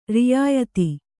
♪ riyāyati